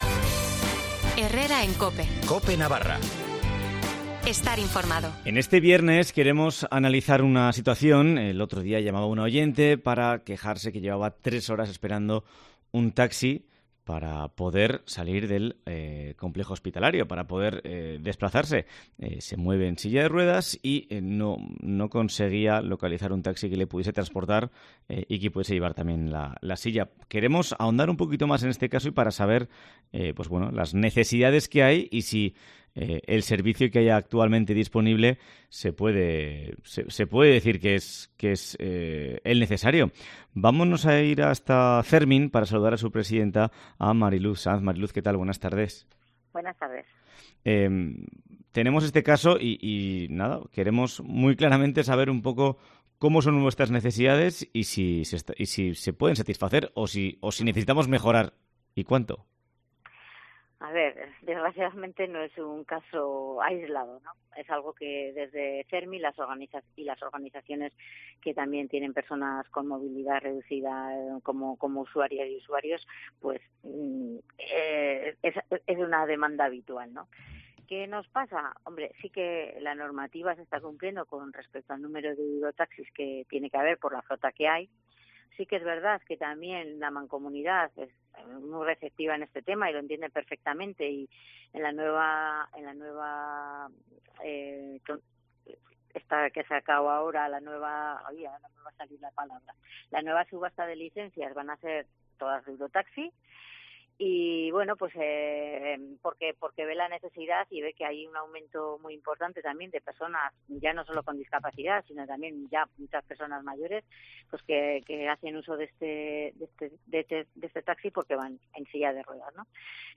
Una oyente se pone en contacto con COPE Navarra para explicar su situación y hablamos con CERMIN y la Mancomunidad de la Comarca de Pamplona para...